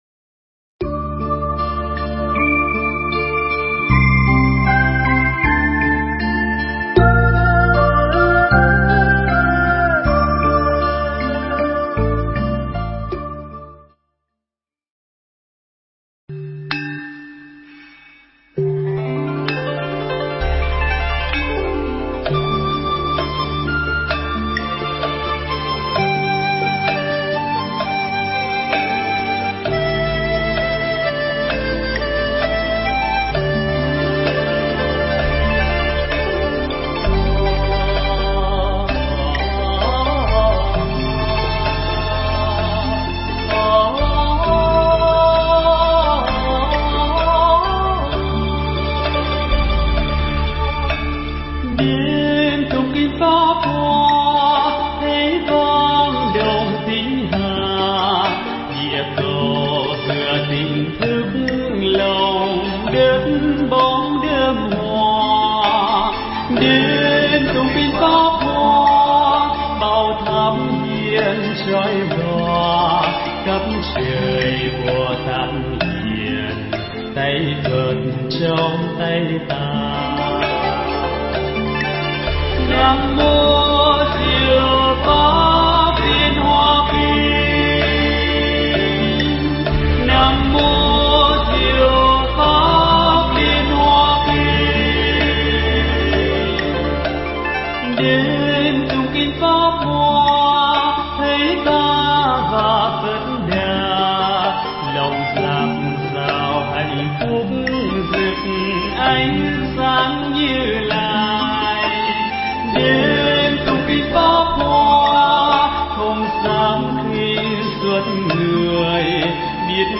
Nghe Mp3 thuyết pháp Diệu Pháp Liên Hoa Kinh Phẩm Ví Dụ